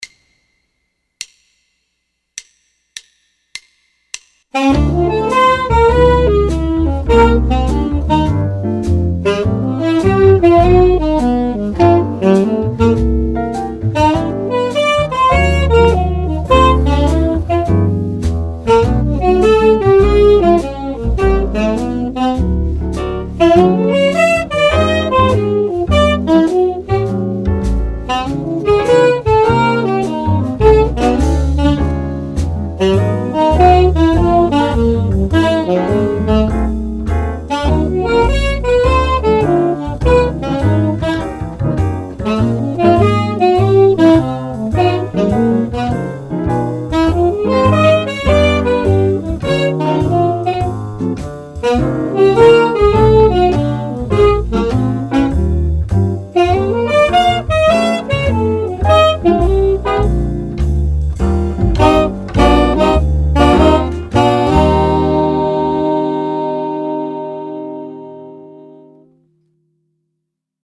You are hearing 4 saxophones with no added effects.